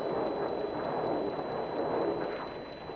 volcán en erupción.
volcan1.wav